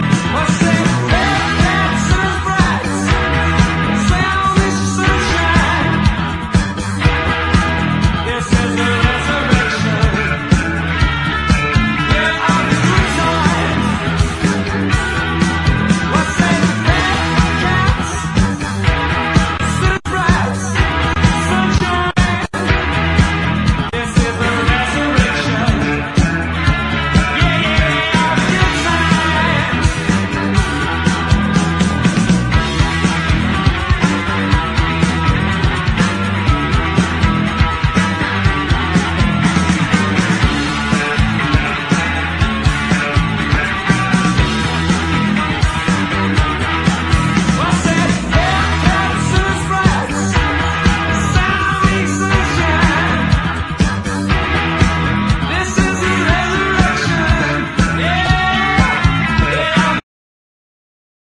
NEW WAVE / OLD SCHOOL
オールド・スクールなニューウェイヴ・ラップ！
ストレンジなダブ処理にも驚きなオトボケ・ニューウェイヴ・スウィング！